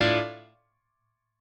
admin-leaf-alice-in-misanthrope/piano34_6_010.ogg at main